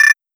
rc-alert.wav